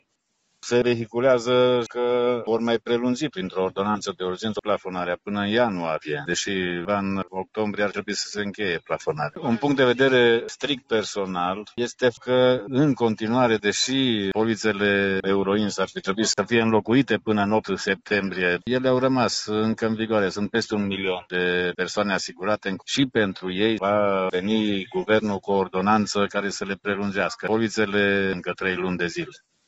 Pe de altă parte, conducătorii auto sunt în general resemnați în privința evoluției prețurilor: